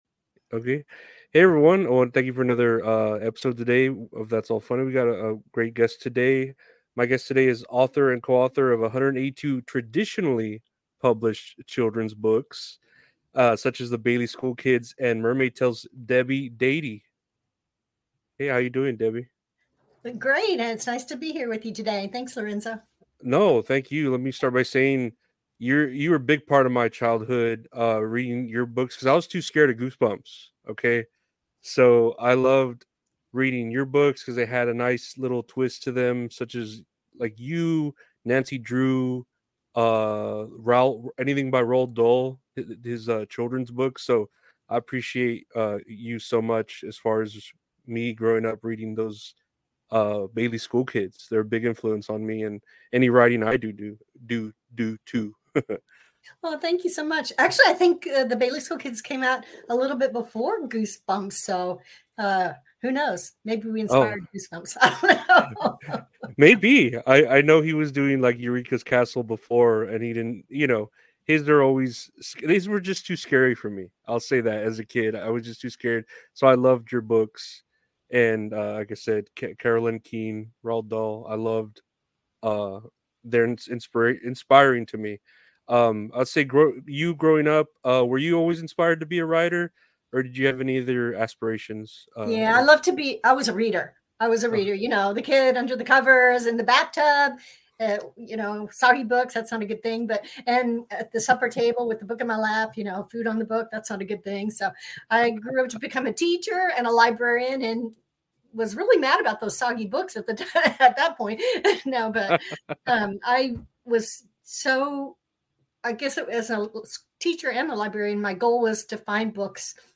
Interview with Debbie Dadey